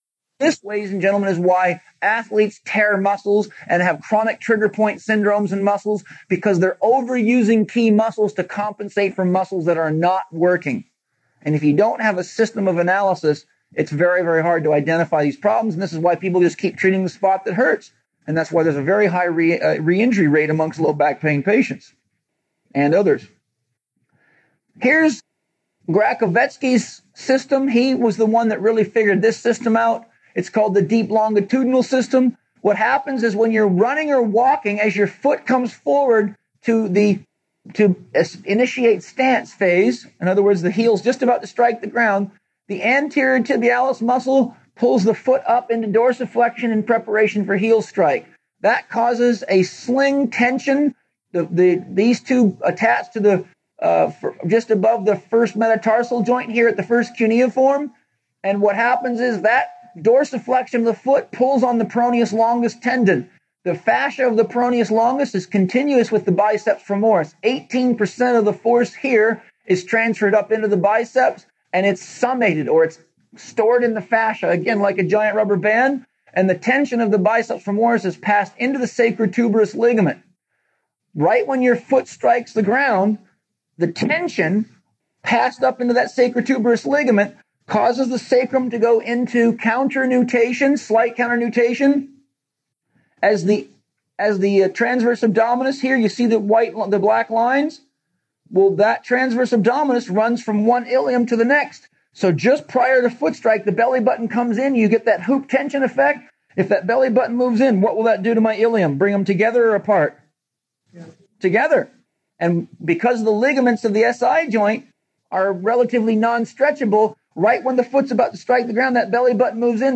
More people suffer from back pain than any other orthopedic injury, leading to extensive use of back corsets and weight belts for the proposed prevention and treatment of back injury. In this lecture